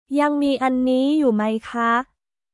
ヤン・ミー・アンニー・ユー・マイ・カ？